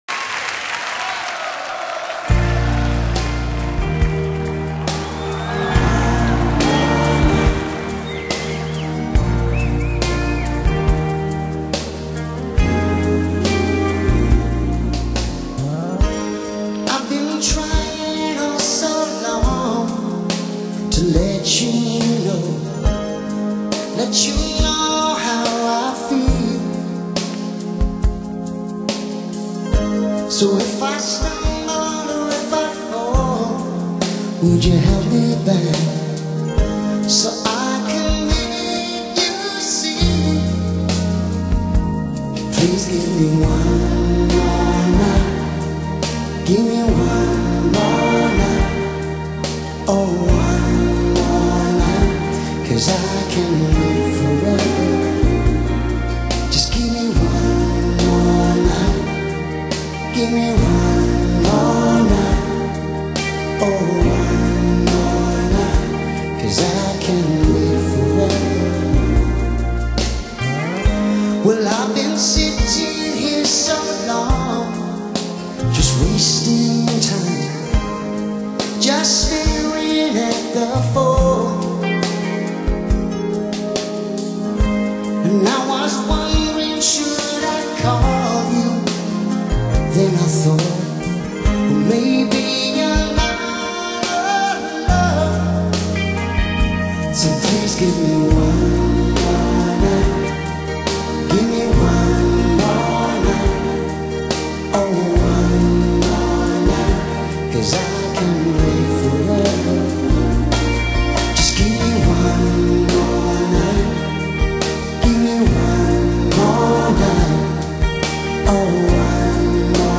Disc 1：Live At Bercy, Paris